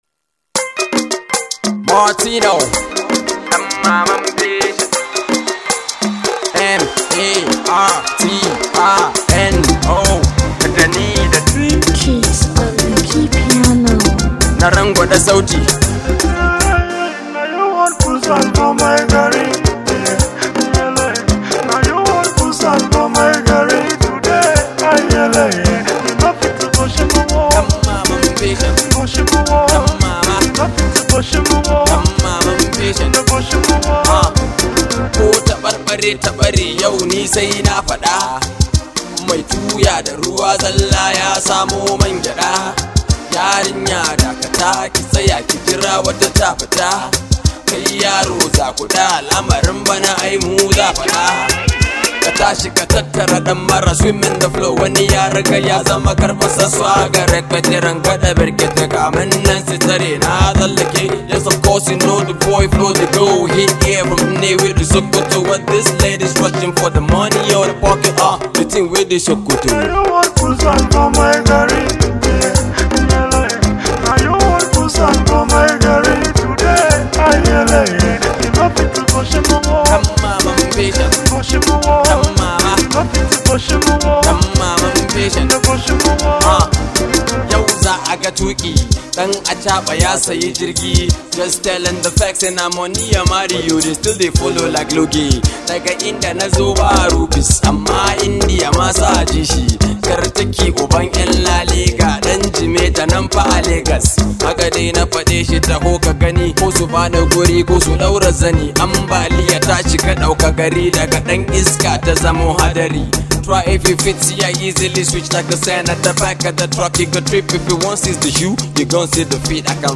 Alternative Pop
Street Pop